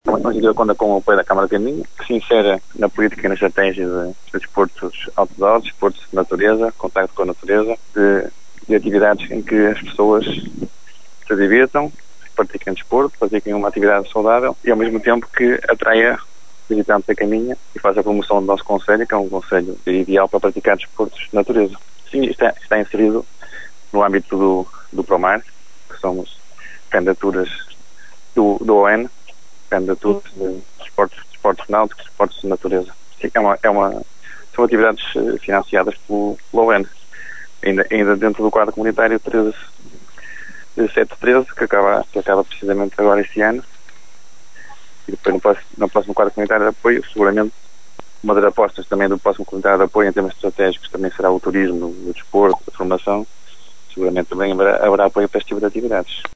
O vereador responsável pelo pelouro do Desporto, Rui Teixeira ,diz que é uma aposta da autarquia o desporto outdoor e, por isso, vai continuar a apoiar as iniciativas privadas realizadas com o obejctivo da promoção deste tipo de desporto.